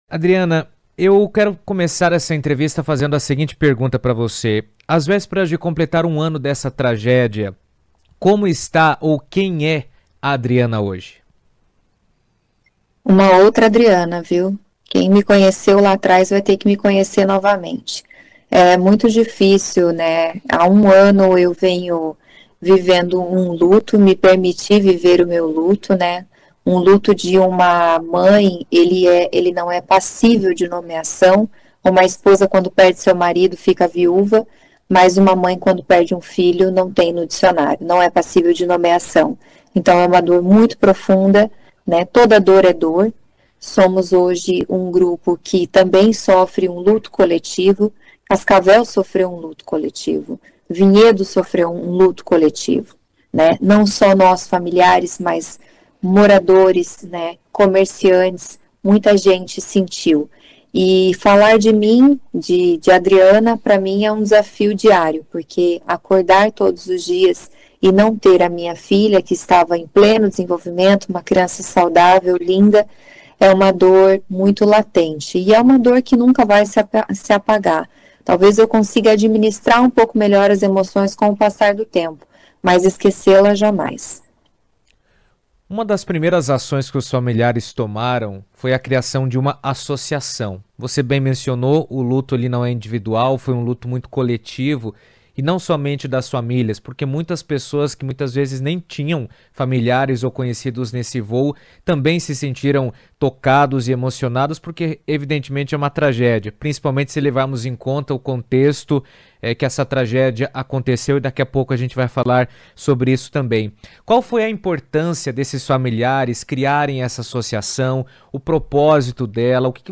Famílias foram ouvidas e autoridades questionadas.